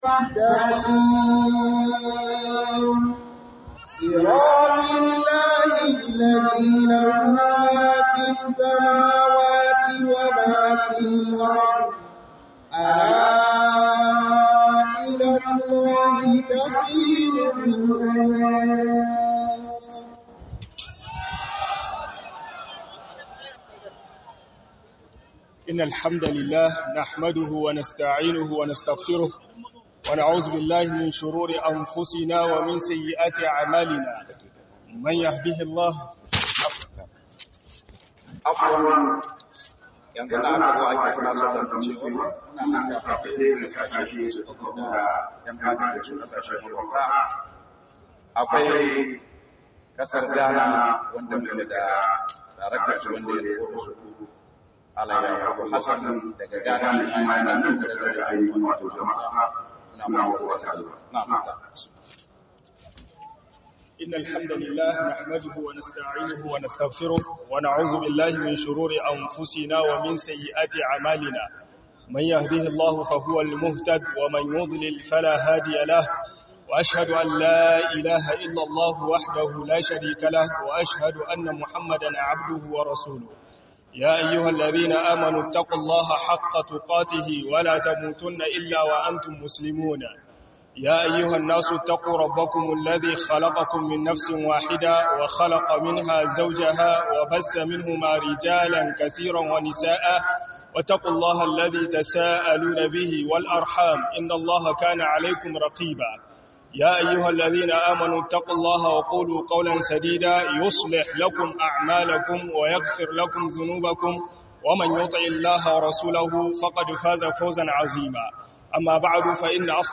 GAGAWTA YIN AIKIN ALHERI - HUDUBA